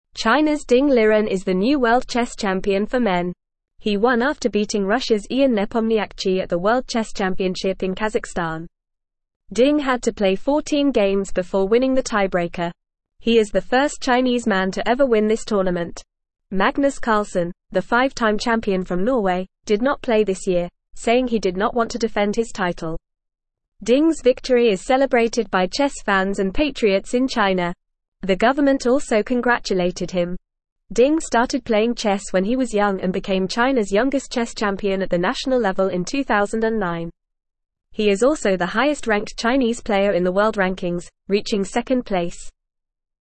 Fast
English-Newsroom-Beginner-FAST-Reading-Chinas-Ding-Liren-World-Chess-Champion.mp3